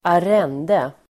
Uttal: [²ar'en:de]